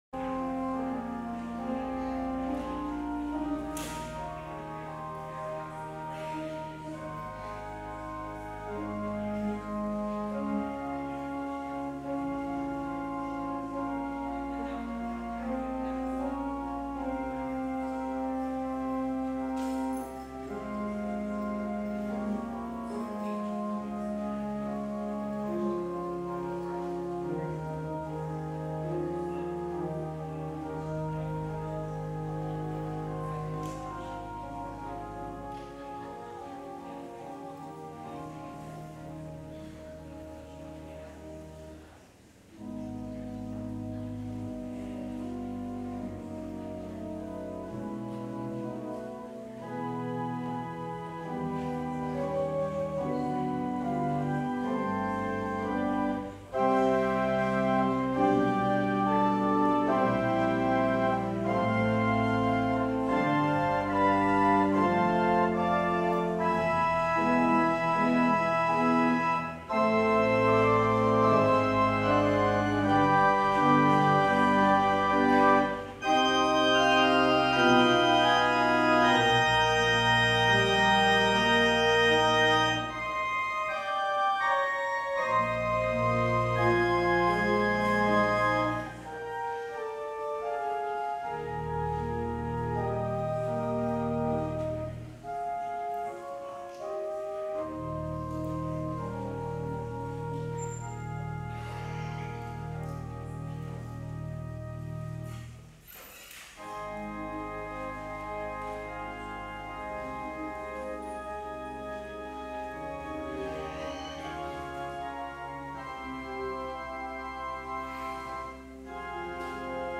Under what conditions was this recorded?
October 6, 2019 Service